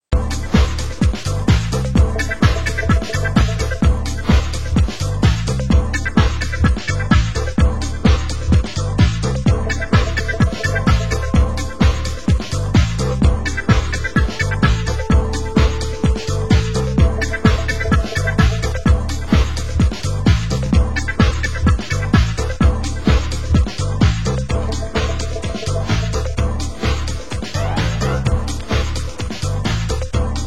Genre: Deep House
Genre: Euro House